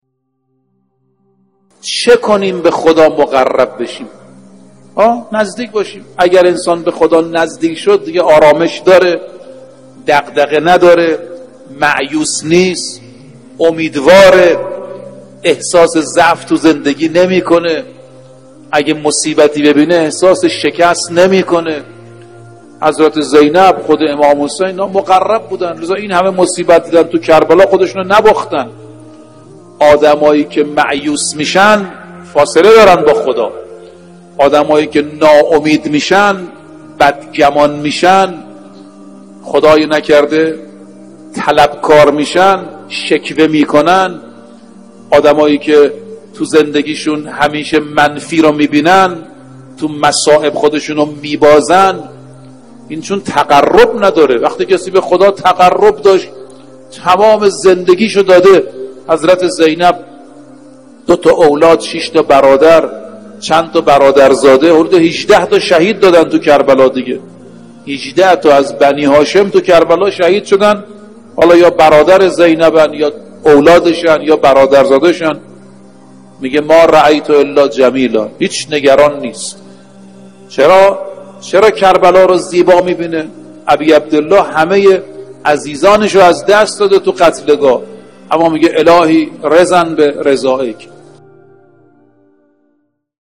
مجموعه پادکست «جلوه‌ای از بندگی» با کلام اساتید به نام اخلاق به کوشش ایکنا گردآوری و تهیه شده است، که بیست وپنجمین قسمت این مجموعه با کلام حجت‌الاسلام‌والمسلمین ناصر رفیعی با عنوان «چه کنیم به خداوند نزدیک شویم» تقدیم مخاطبان گرامی ایکنا می‌شود.